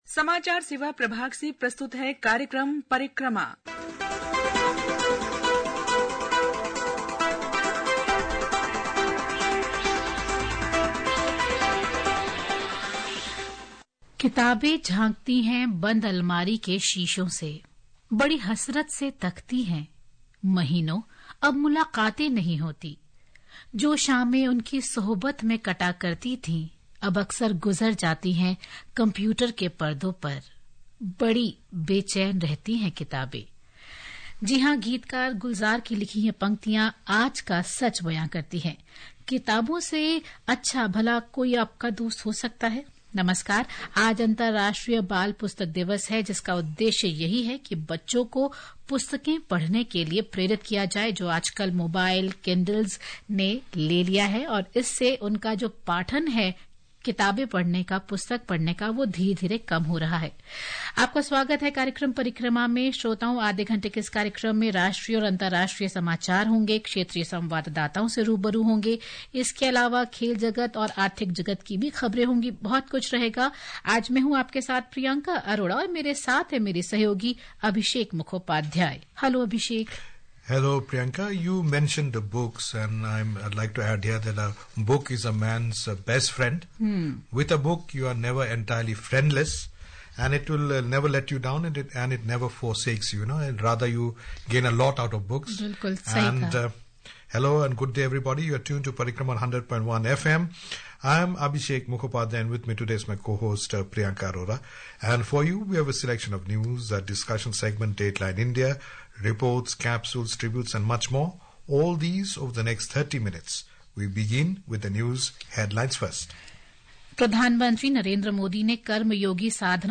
This audio news bulletin titled Hourly News in the category Hourly News .